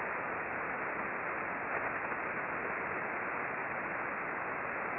The ambient RF noise level in this direction is fairly high (over 300k K).
We observed mostly S-bursts that shifted from receiver to receiver during the bursting periods.